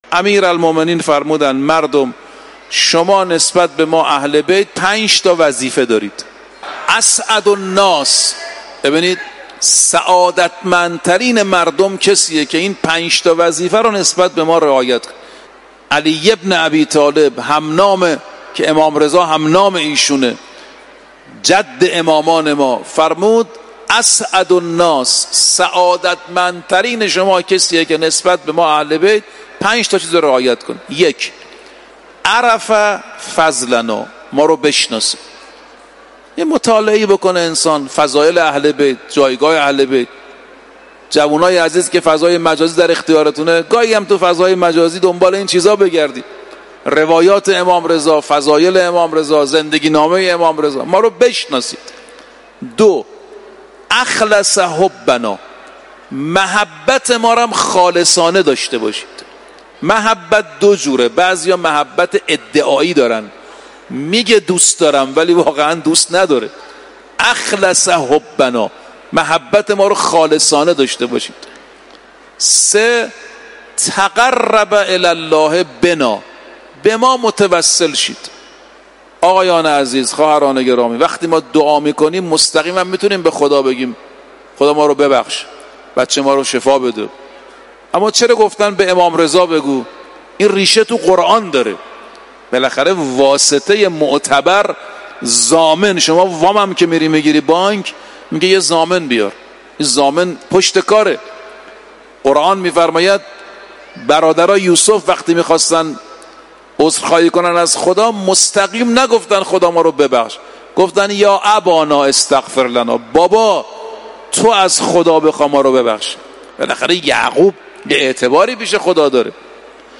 Həmin çıxışı siz hörmətli oxucularımıza təqdim edirik.